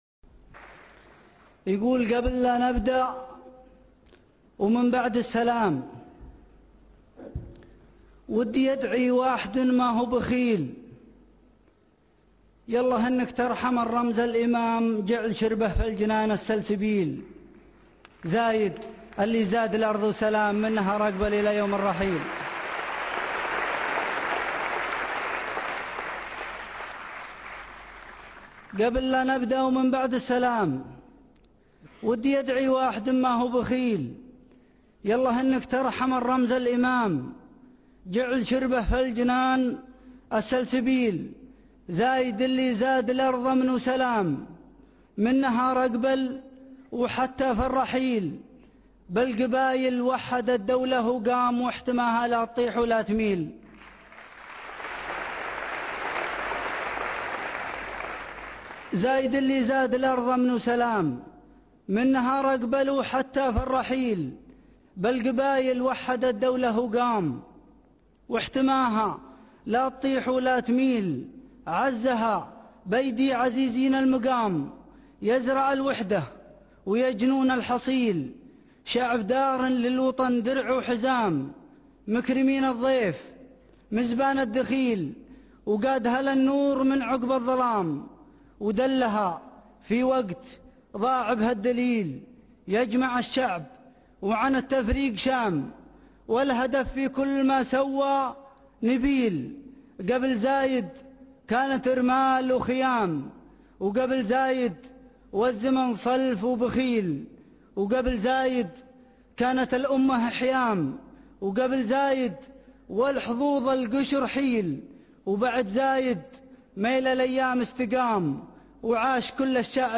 ملتقى دبي العاشر 2011